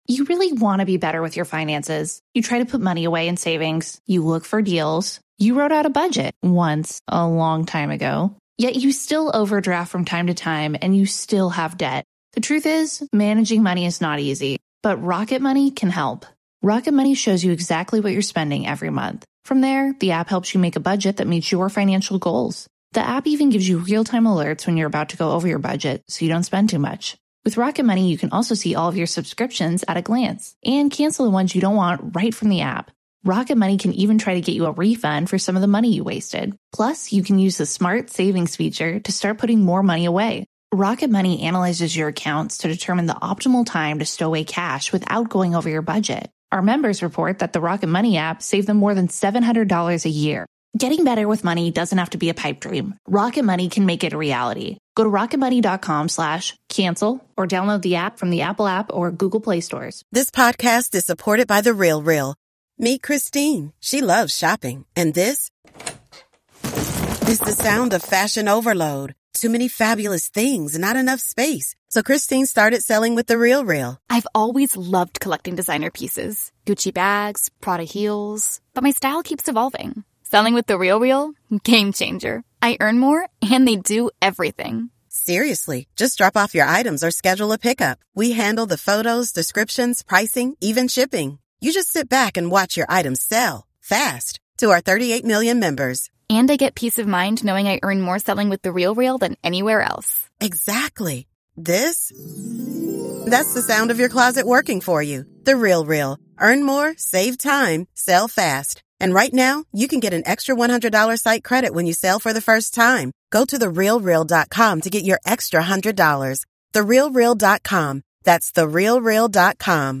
On this week's chaotic episode recap, the ladies discuss the chaotic No Strings Attached. Topics of discussion include: the "Cleveland" fake out, feeling gaslit by the plot, soup/sandwich switching, Charlotte's menopause, not being the white lady who writes a check, Lily's traumatizing period, Che's mixed signals, LTW's fabulous outfit, Nya meets the gang, shipping Steve/Carrie, our predictions for the finale, your calls, AND SO MUCH MORE!